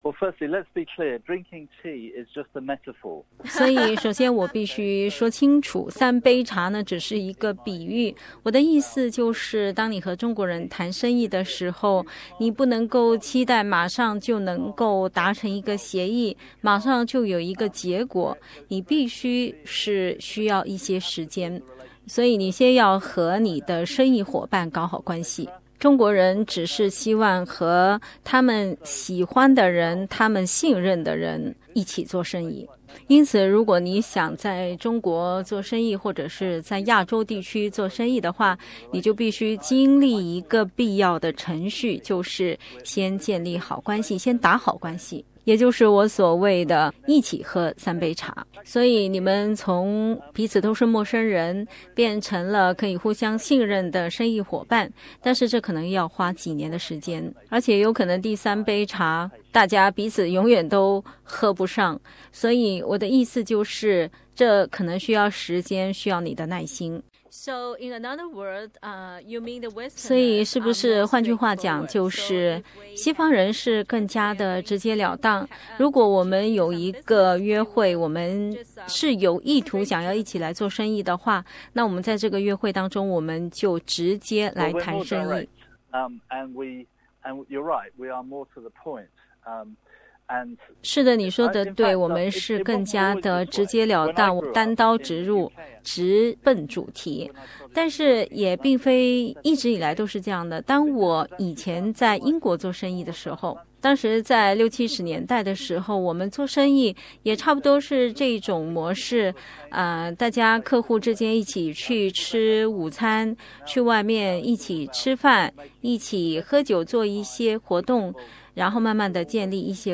接受本台采访。